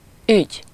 Ääntäminen
Synonyymit deal Ääntäminen : IPA : /tɹæn.ˈzæk.ʃən/ US : IPA : [tɹæn.ˈzæk.ʃən] Tuntematon aksentti: IPA : /tɹæn.ˈsæk.ʃən/ Lyhenteet ja supistumat trans tr.